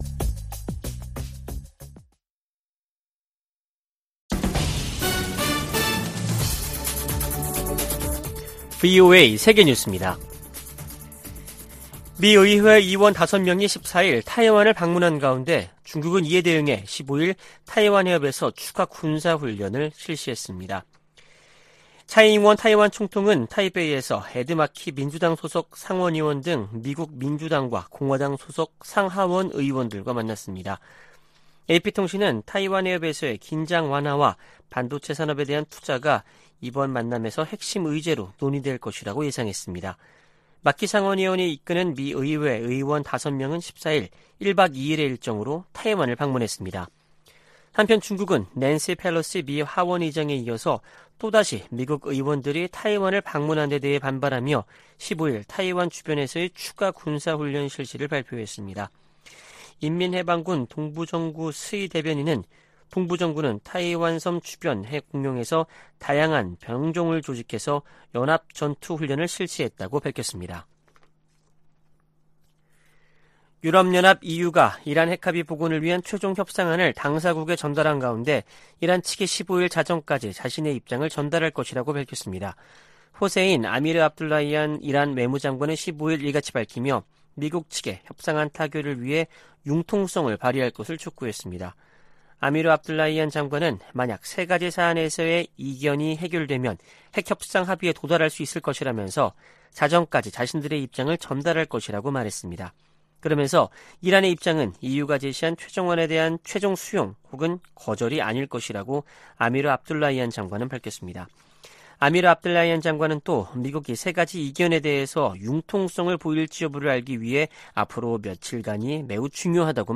VOA 한국어 아침 뉴스 프로그램 '워싱턴 뉴스 광장' 2022년 8월 16일 방송입니다. 윤석열 한국 대통령이 광복절을 맞아, 북한이 실질적 비핵화로 전환하면 경제를 획기적으로 개선시켜주겠다고 제안했습니다. 중국의 ‘3불 1한’ 요구에 대해 해리 해리스 전 주한 미국대사는 중국이 주권국가에 명령할 권리가 없다고 지적했습니다. 중국이 낸시 펠로시 미국 하원의장의 타이완 방문을 구실로 도발적인 과잉 반응을 지속하고 있다고 백악관 고위관리가 규탄했습니다.